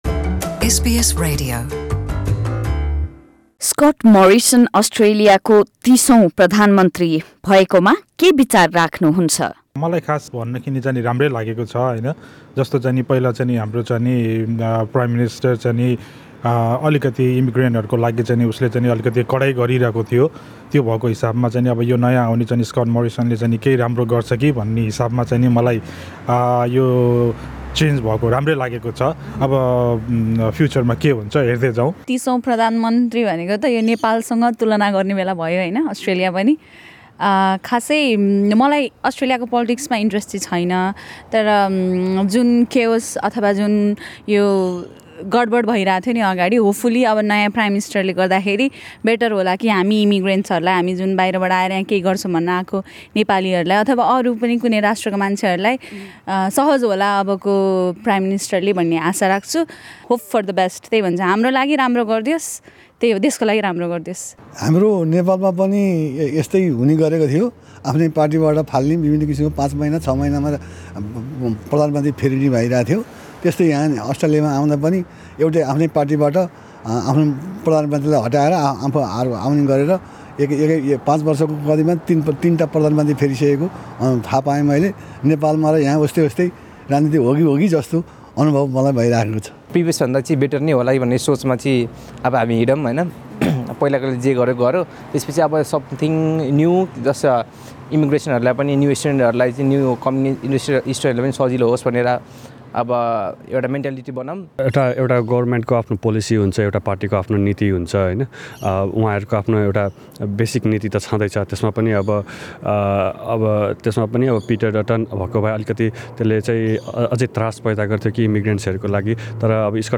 Nepalis in Australia speaking to SBS Nepali about what they think of the new Prime Minister Scott Morrison.